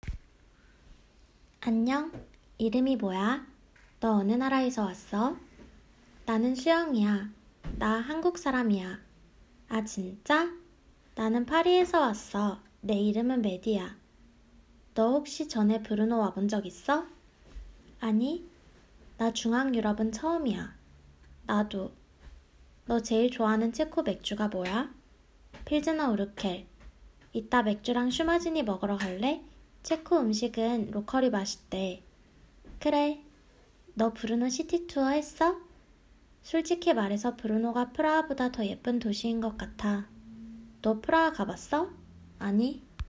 5_conversation.m4a